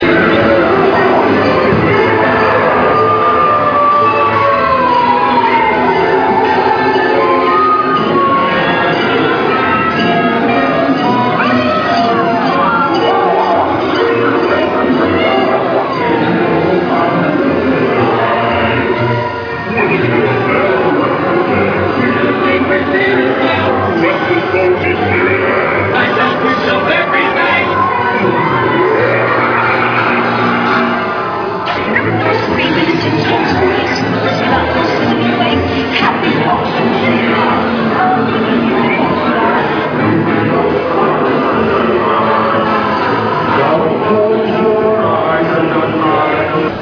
Sound from inside the Haunted Mansion.